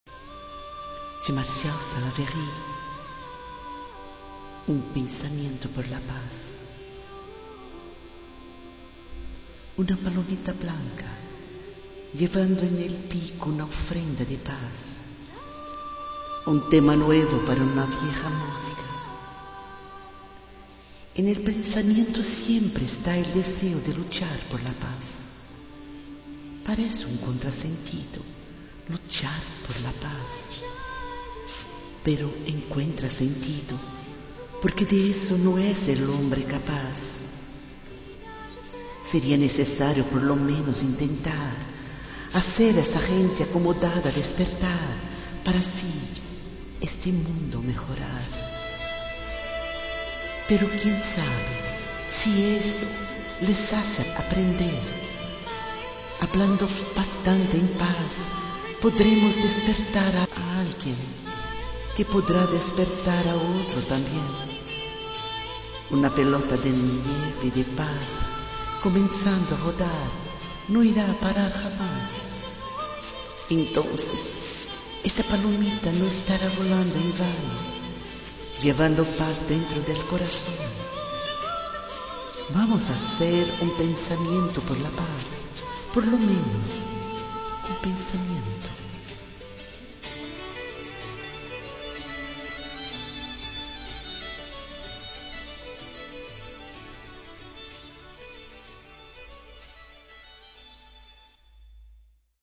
Si desea escuchar la Mensaje de fondo después de la Lectura de la
con su dulce voz...